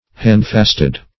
handfast \hand"fast`\, v. t. [imp. & p. p. handfasted; p. pr.
handfasted.mp3